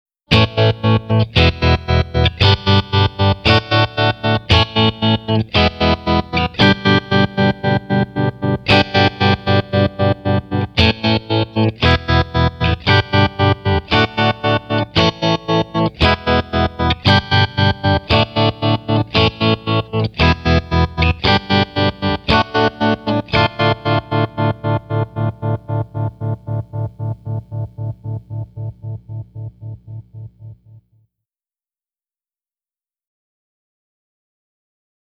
Tremolo Pedal
The Voodoo Lab Tremolo re-creates the buttery, seductive tone of a vintage tube amp tremolo.
This pedal also features a slope control which lets you adjust the feel from a traditional soothing tremolo to a hard "machine-gun" stutter.
Stutter.mp3